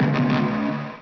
BEEPBASS.WAV